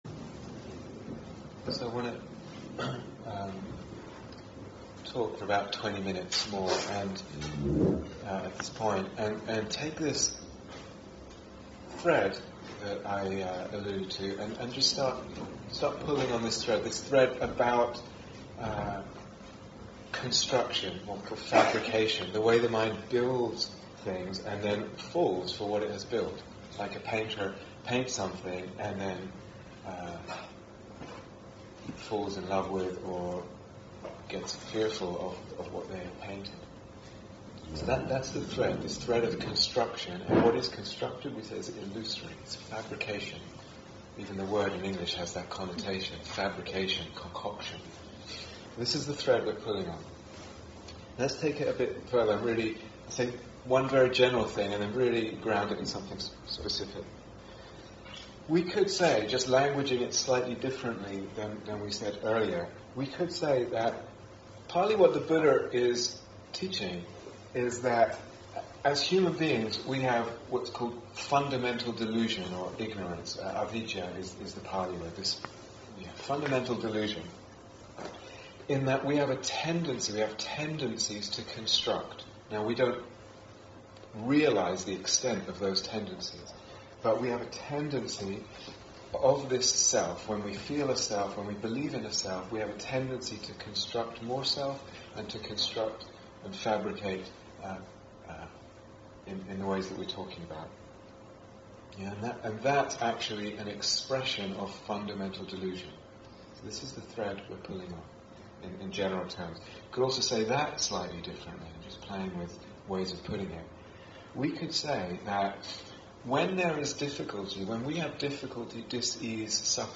Day Retreat, London Insight 2012